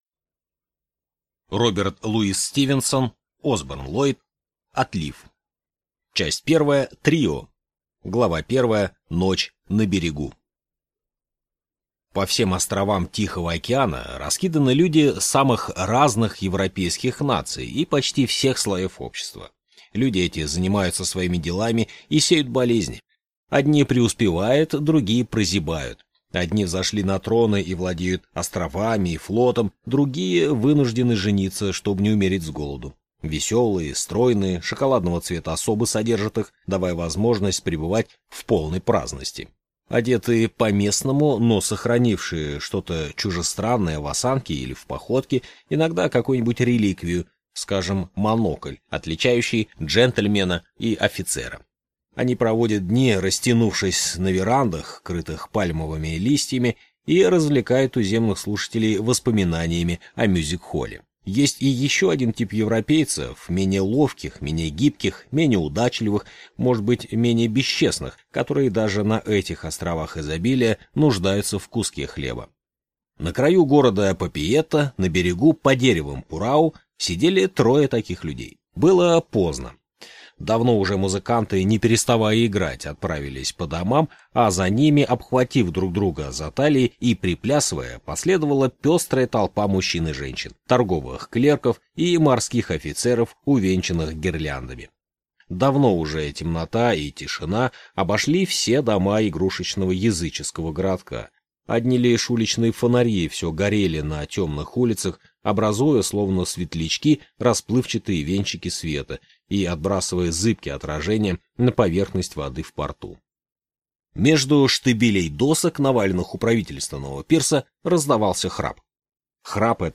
Аудиокнига Отлив | Библиотека аудиокниг